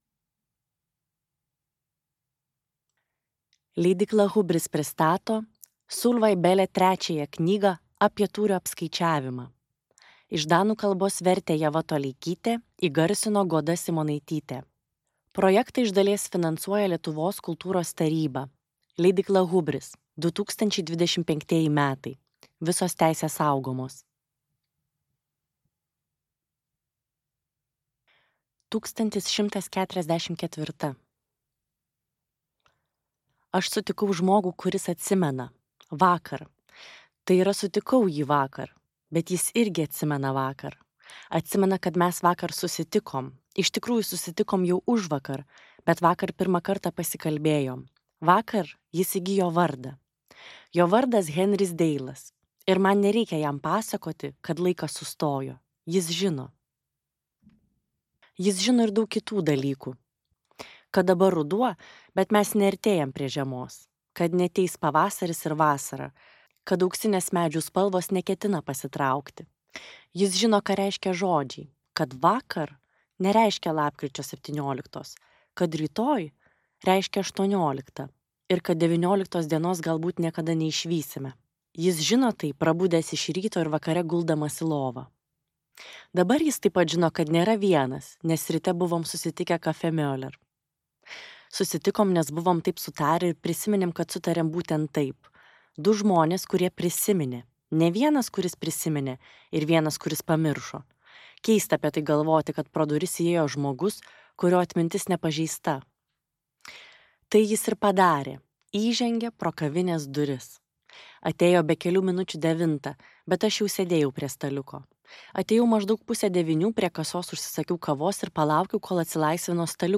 Danų autorės Solvej Balle audioknyga „Apie tūrio apskaičiavimą“ – trečioji dalis (iš septynių).
Skaityti ištrauką play 00:00 Share on Facebook Share on Twitter Share on Pinterest Audio Apie tūrio apskaičiavimą.